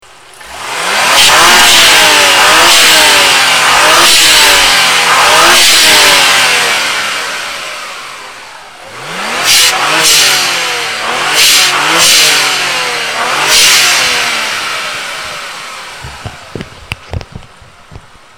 blowoff1ed.mp3